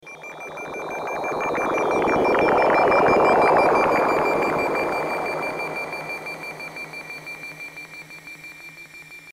Downtempo & Ambient
I do have another instrument fading in and out throughout the piece.
It's intentionally a bit loose rhythmically, and was not quantized, particularly the hand drum parts.
That's Dimension Pro's "Sitar and Tambouras" patch, with some moderately heavy reverb.  Sitars always tend to have those droning resonant overtones, and I guess the reverb is accentuating them.